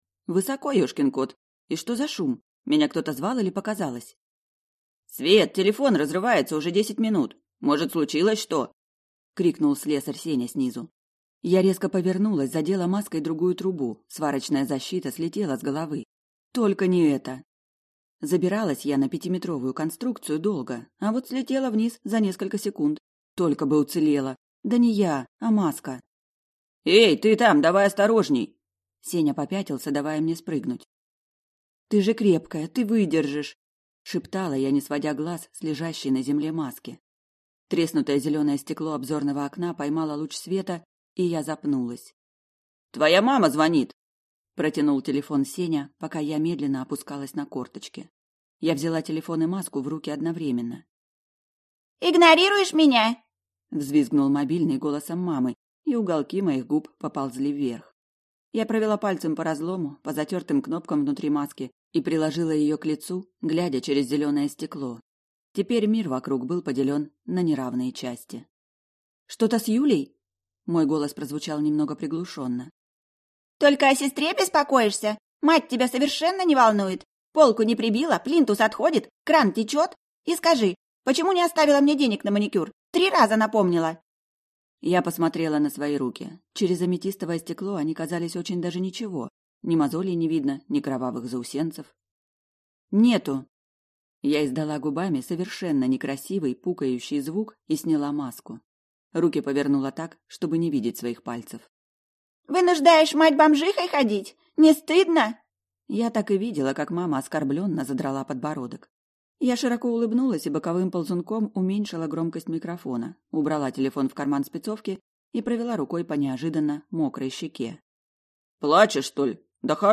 Аудиокнига Оборотень по объявлению. Майконг | Библиотека аудиокниг